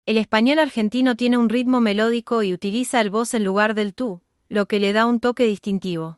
• Argentinian Spanish has a melodic rhythm and uses vos instead of tú, giving it a distinctive flair.
acento-argentino.mp3